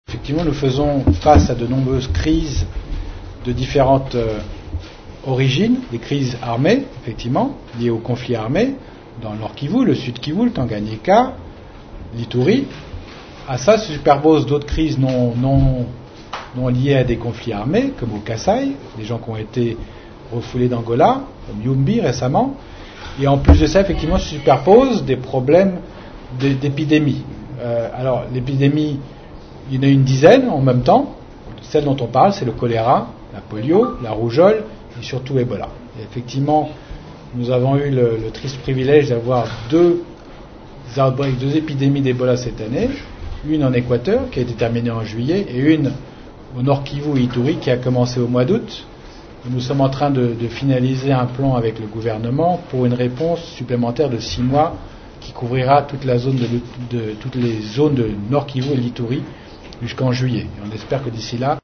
L’agence onusienne a fait cette annonce mardi 29 janvier au cours d’une conférence de presse à Kinshasa.